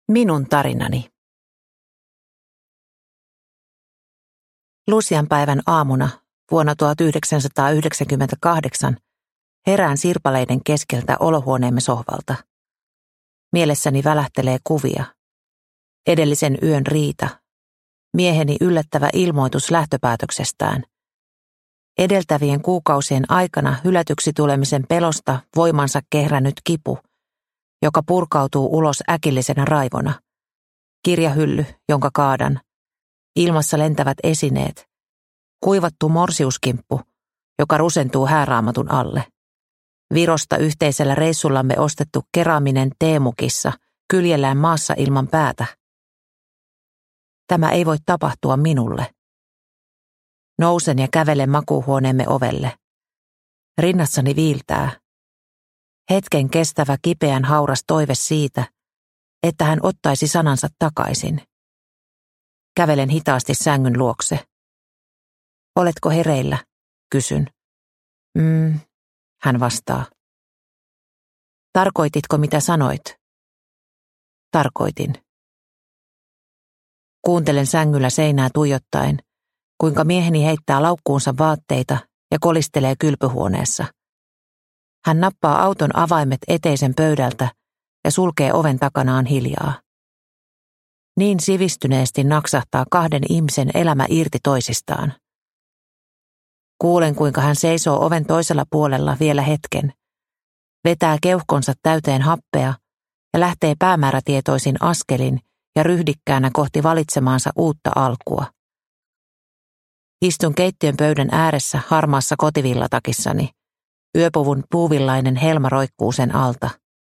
Sinä selviät kyllä – Ljudbok – Laddas ner